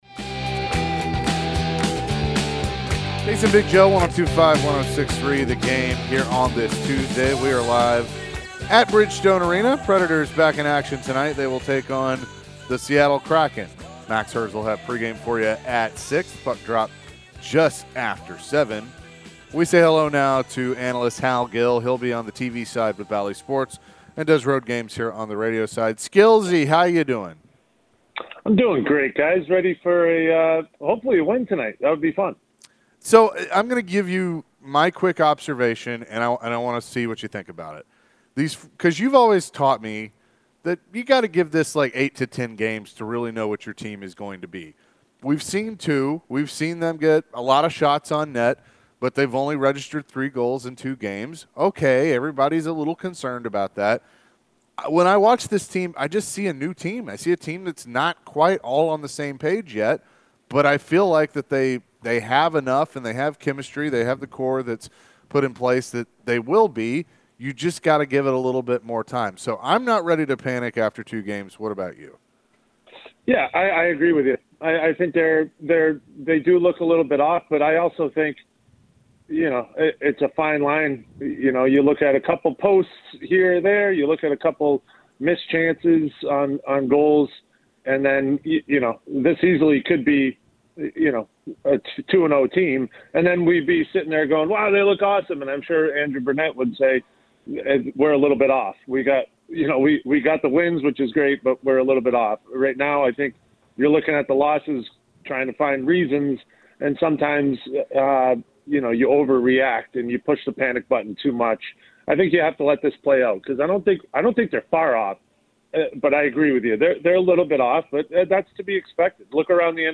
Hal Gill joined the show to share his thoughts on the Preds' start of the 2024 season. Is he worried about the team's slow start?